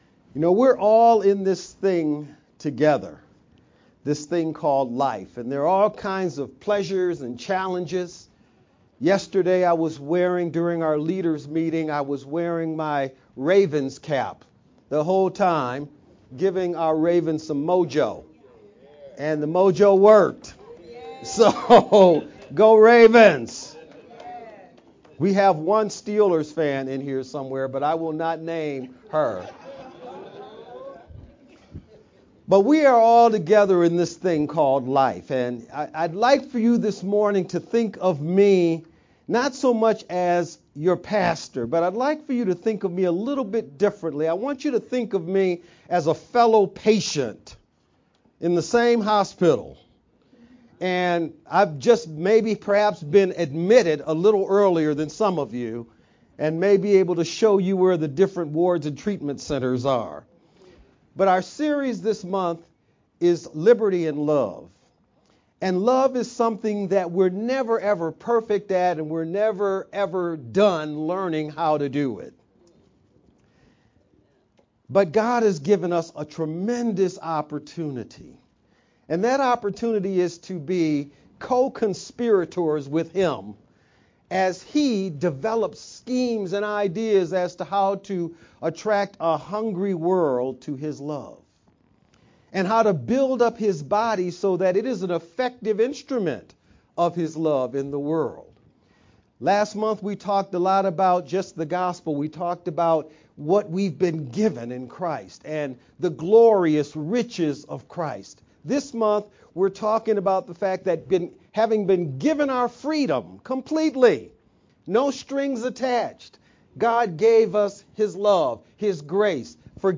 Jan-21st-Sermon-only_Converted-CD.mp3